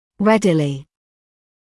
[‘redɪlɪ][‘рэдили]легко, без труда; быстро, без задержки